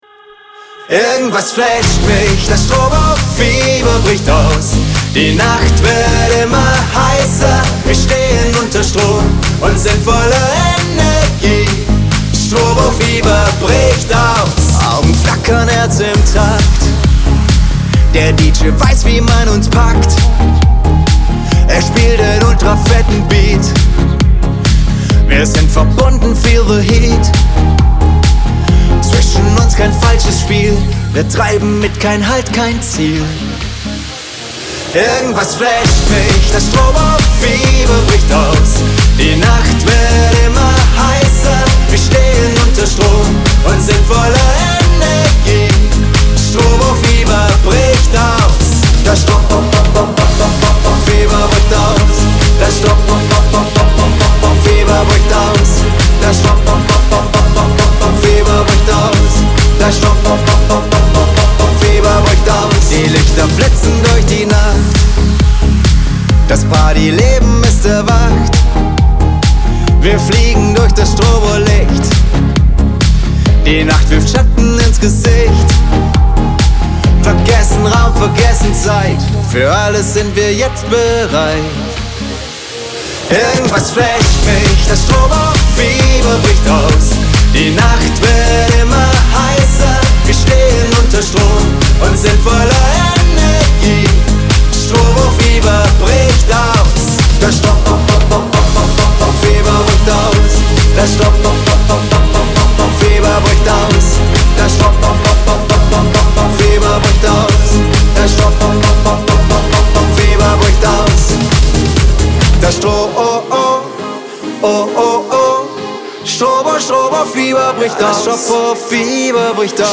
tanzbar, emotional und voller Energie.